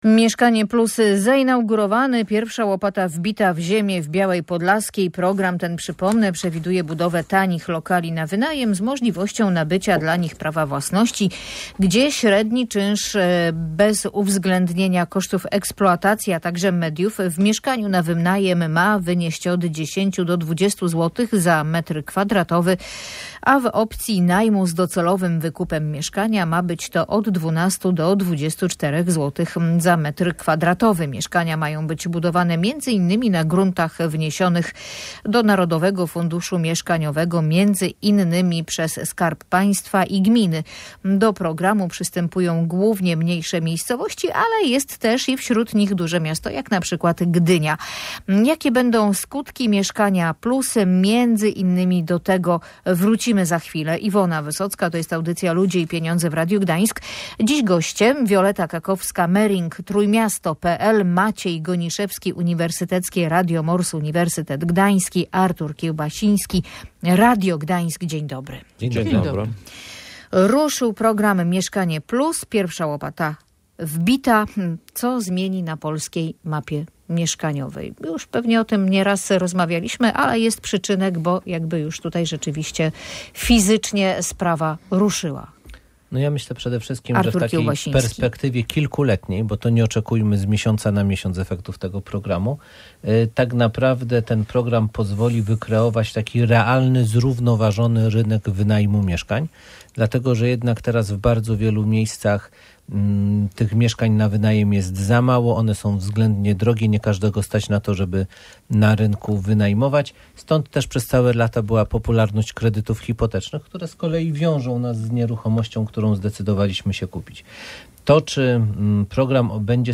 O tym dyskutowali eksperci w audycji Ludzie i Pieniądze.